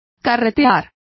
Complete with pronunciation of the translation of carted.